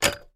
Здесь вы найдете характерные шумы вращающегося диска, гудки ожидания и другие аутентичные эффекты.
Звук опущенной трубки старинного телефона